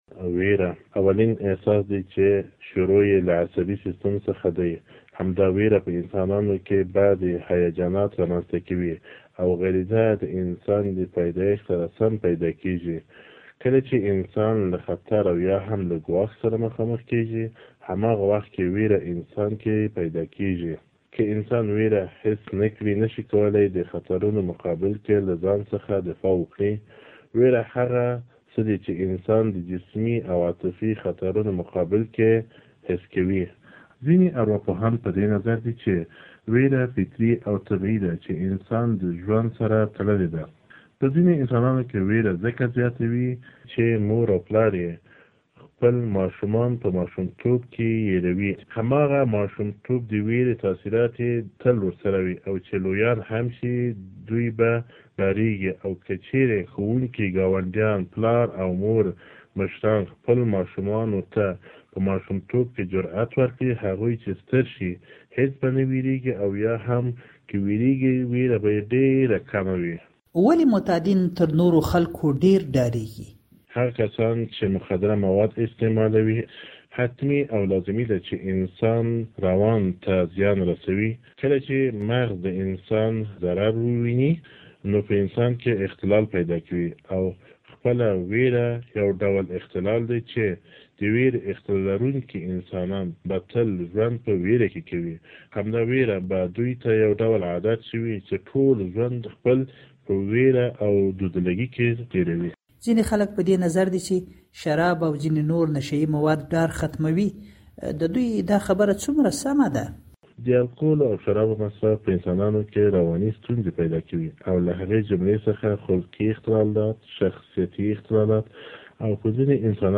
اونېزې خپرونې